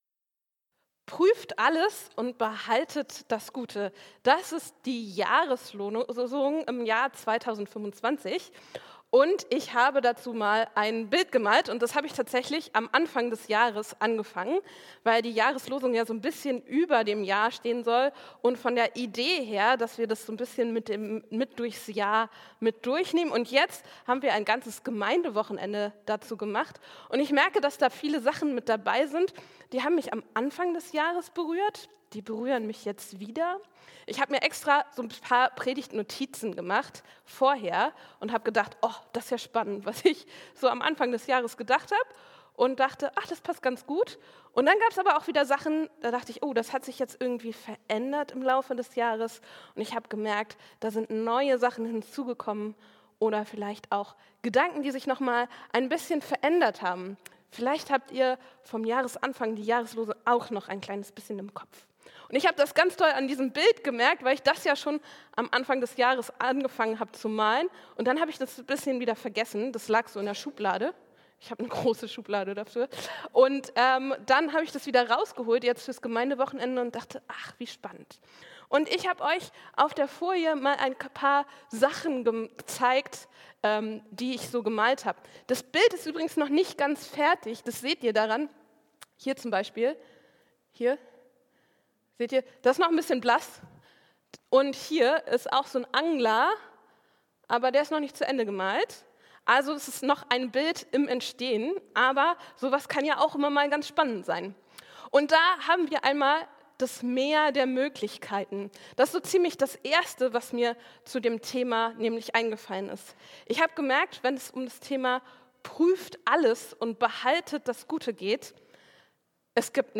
Prüft alles und behaltet das Gute ~ Christuskirche Uetersen Predigt-Podcast Podcast